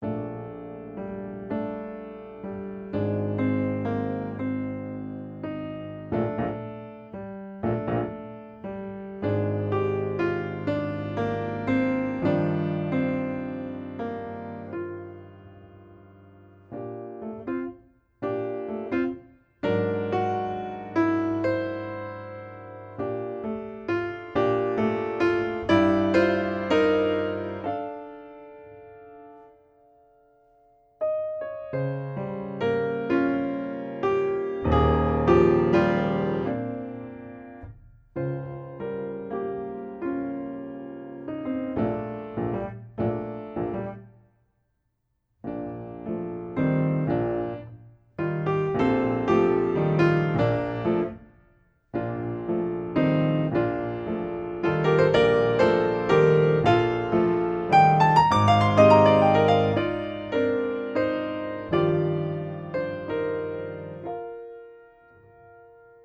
per pianoforte solo